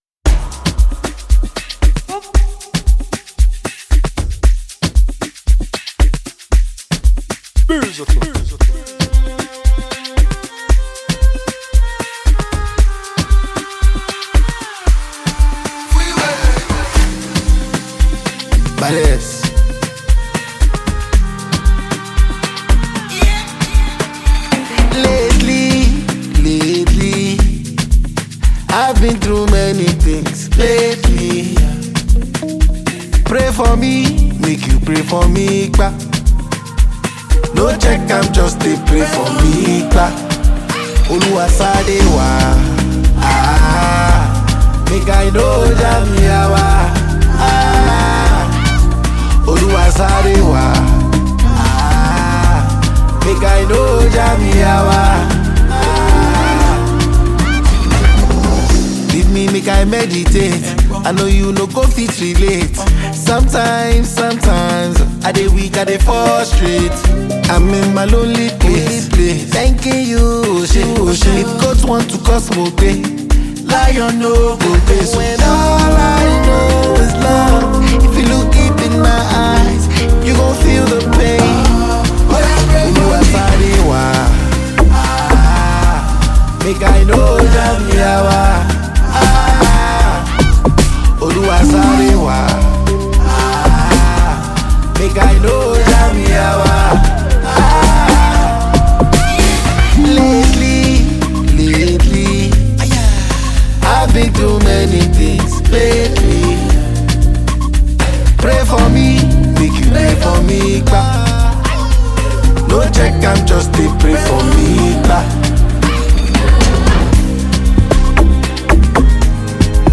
AfroBeats | AfroBeats songs
a smooth, soul-infused track